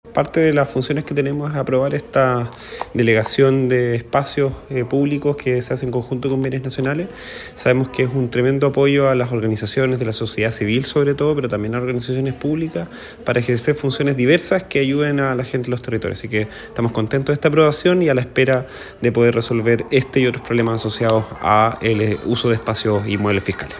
Consejero-Matías-Fernández.mp3